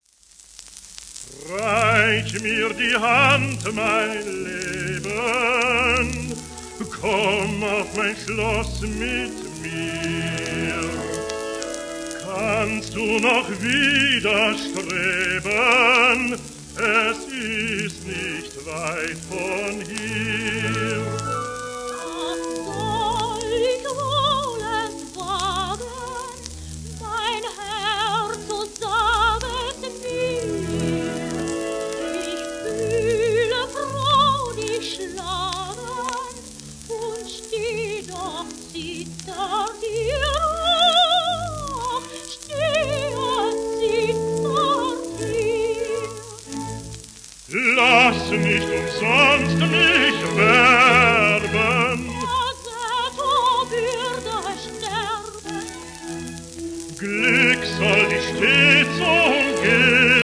エルナ・ベルガー(Sop:1900-90)
w/H.シュルスヌス(br)、Victrlca Ursuleac(sop)、クレメンス・クラウス(cond),ベルリン国立歌劇場管
ドン・ジョヴァンニより「お手をどうぞ」（モーツァルト）:w/H.シュルスヌス(br)
シェルマン アートワークスのSPレコード